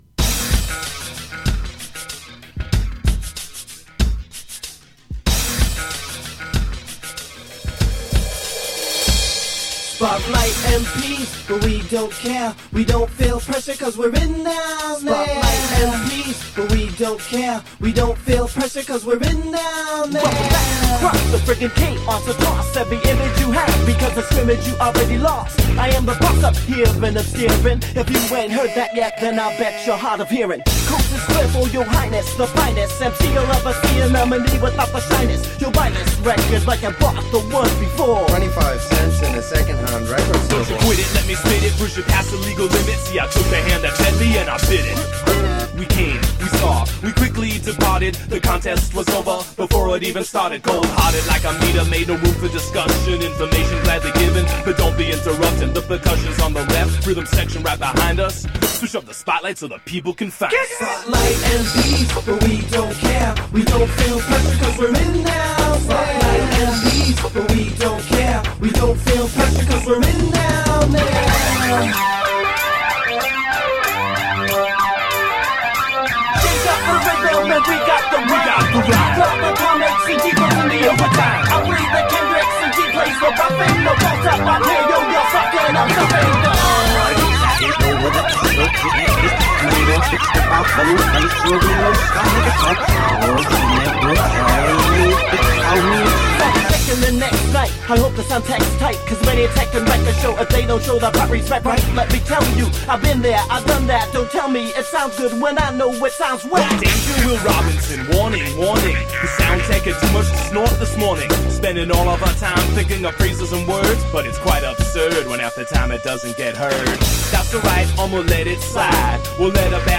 Smart-alecky, 'semi-conscious' hip-hop.
Tagged as: Hip Hop, Other